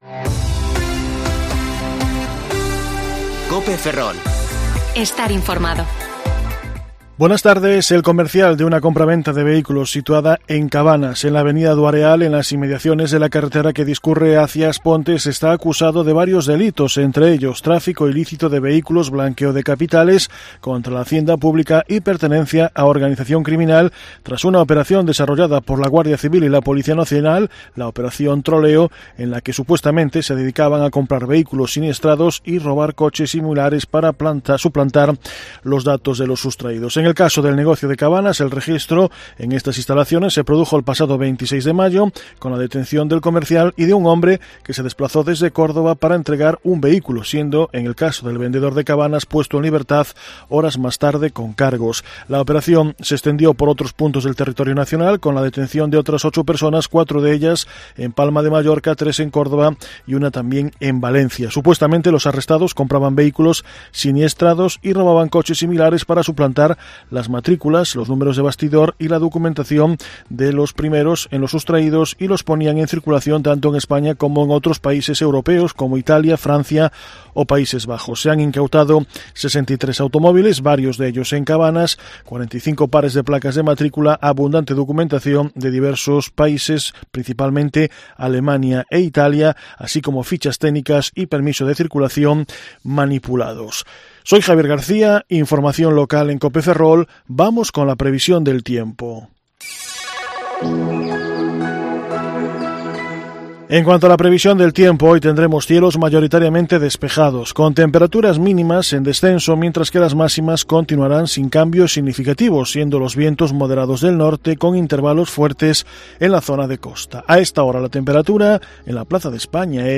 Informativo Mediodía COPE Ferrol 23/6/2021 (De 14,20 a 14,30 horas)